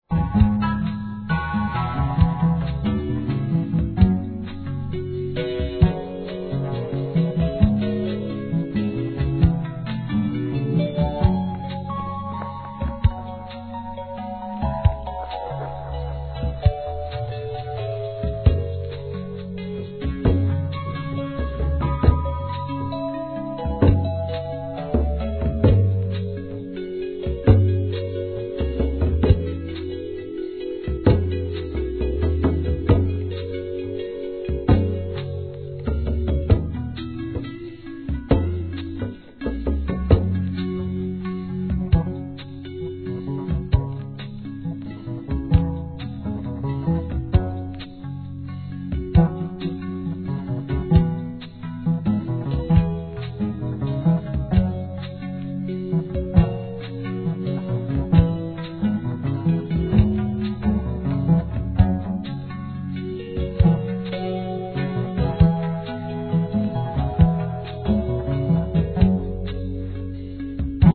店舗 ただいま品切れ中です お気に入りに追加 アフリカン・インストゥルメンタルとシンセサイザーの癒しのコラボ!!